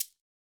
KIN Shaker 2.wav